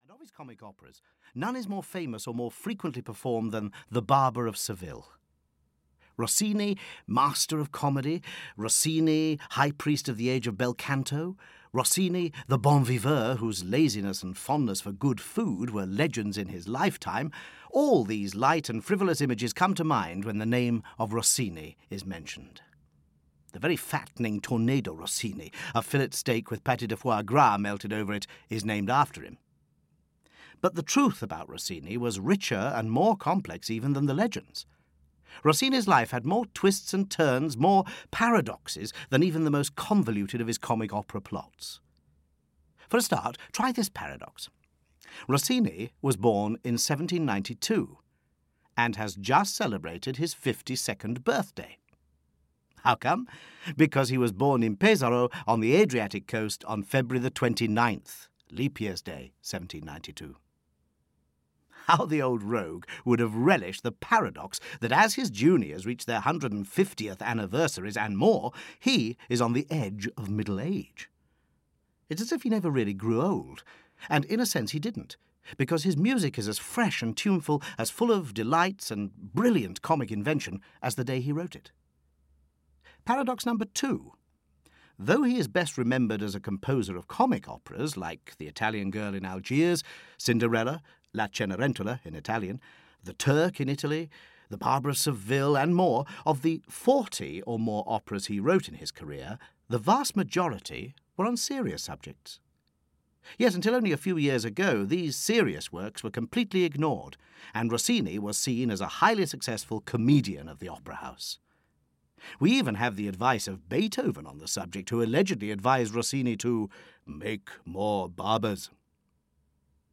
Opera Explained – The Barber of Seville (EN) audiokniha
Ukázka z knihy